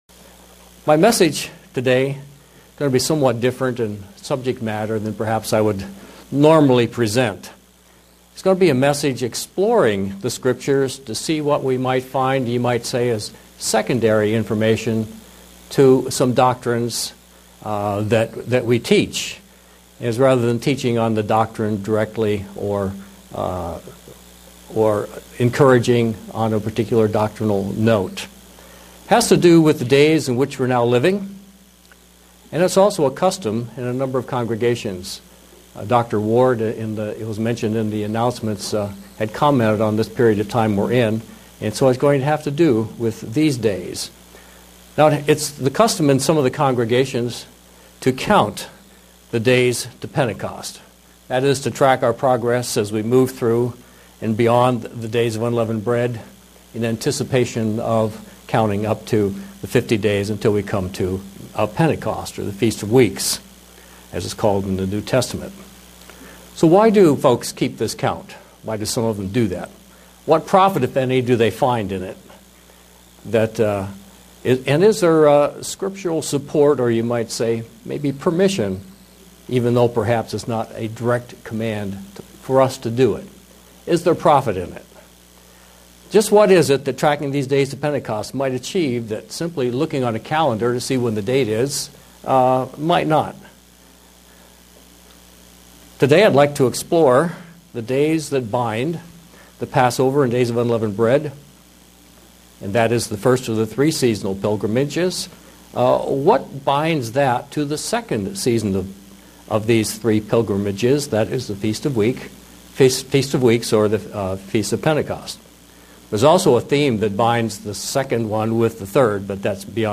Print Things to focus on during the days that lead up to Pentecost. sermon Studying the bible?